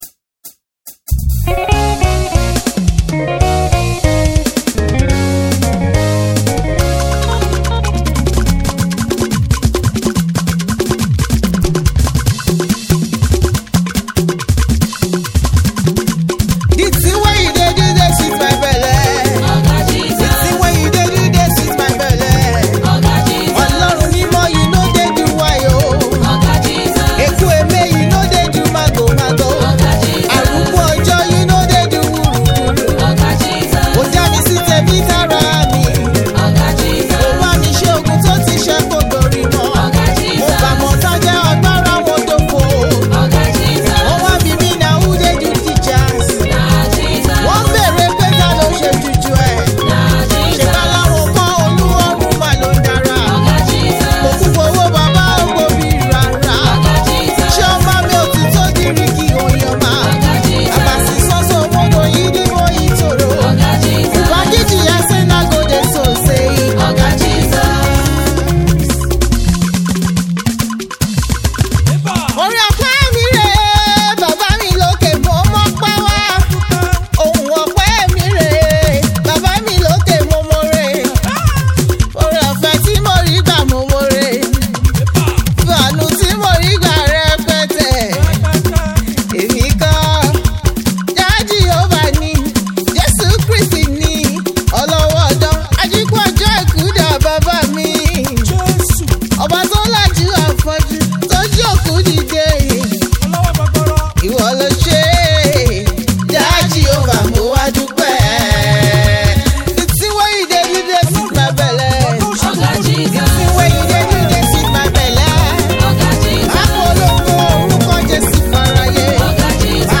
Nigerian gospel artiste
praise single
Heavy on African percussion
a song of praise
Fuji